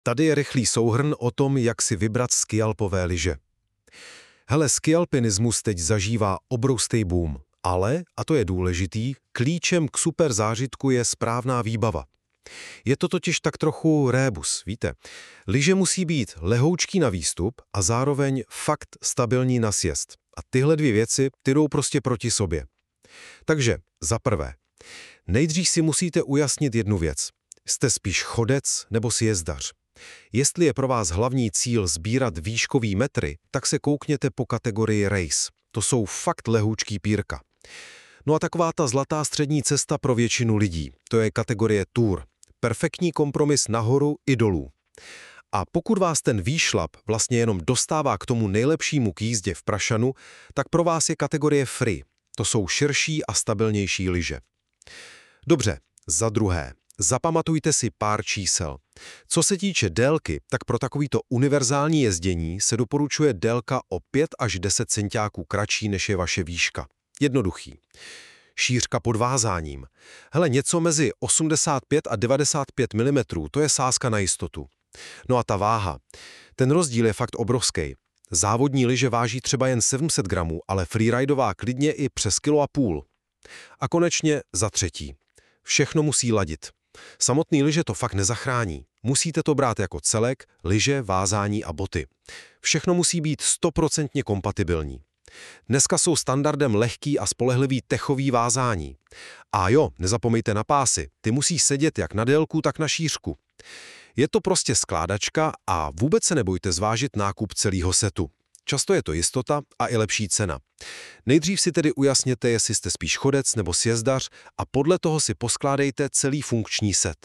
🎧 Alex AI radí